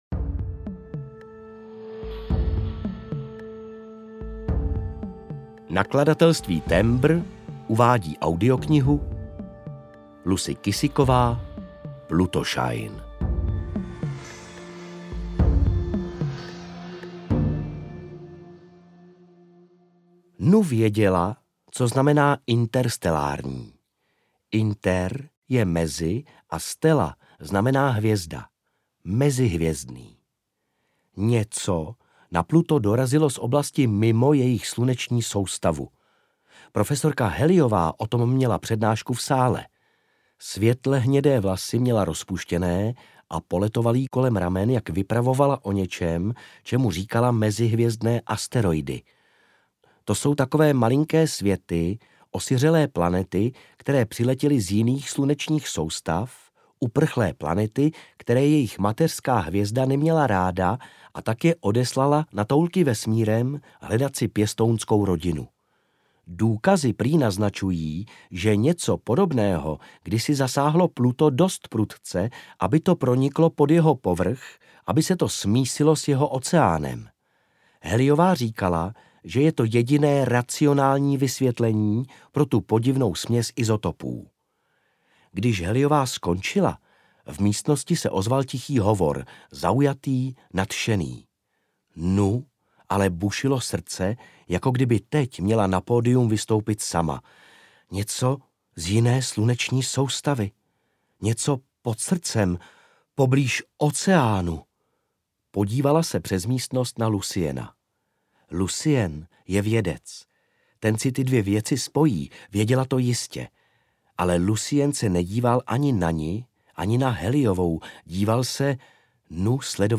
Plutoshine audiokniha
Ukázka z knihy